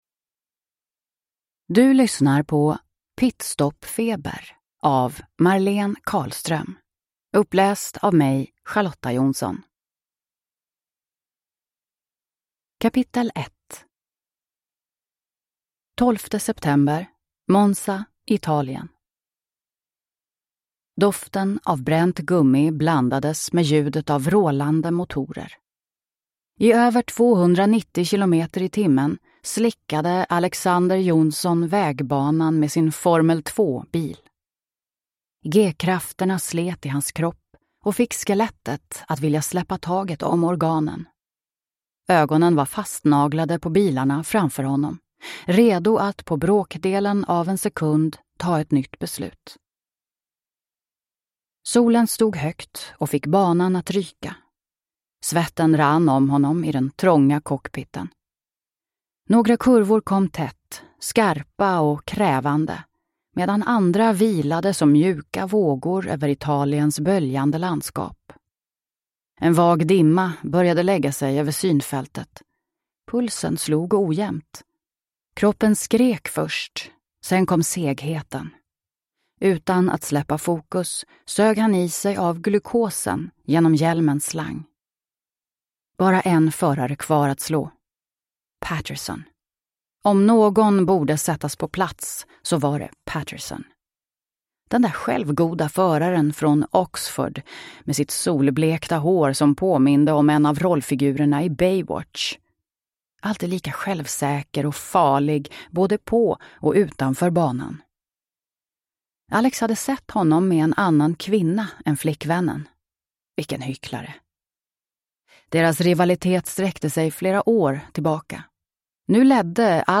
Pit stop feber – Ljudbok